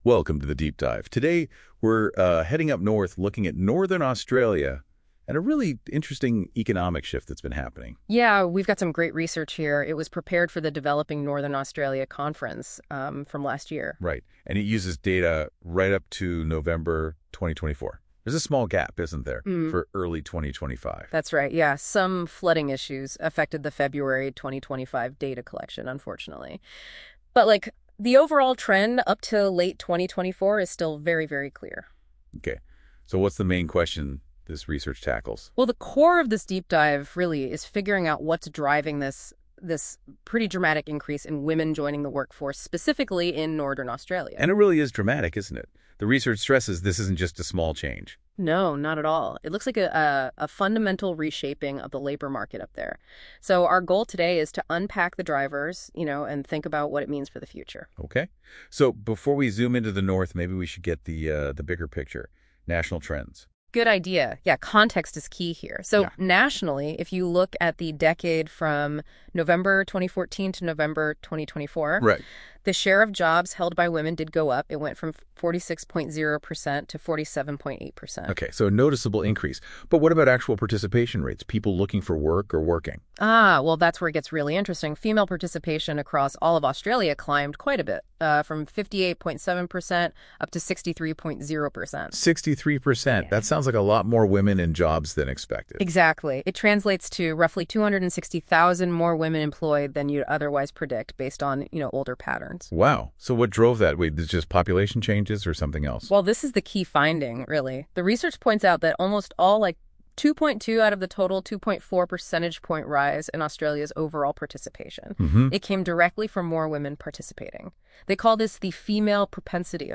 If you would rather listen to an AI-generated podcast about this analysis you can!